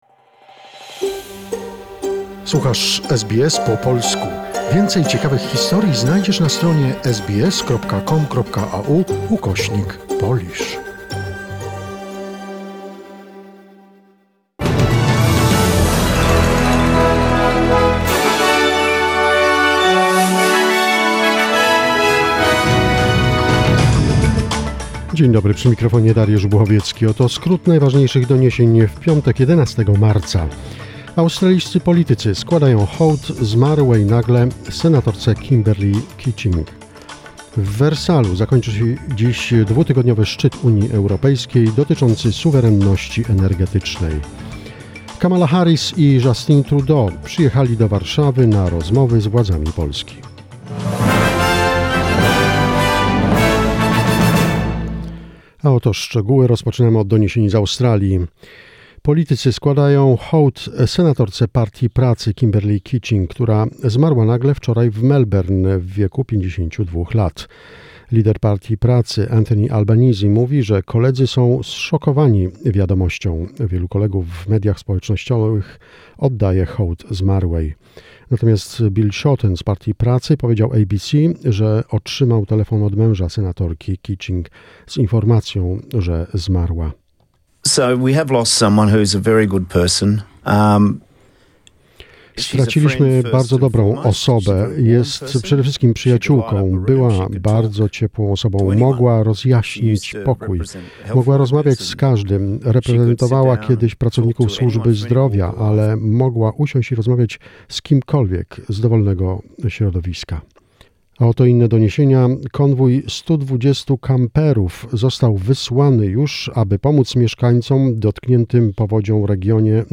SBS News in Polish, 11 March 2022